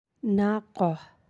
(naqah).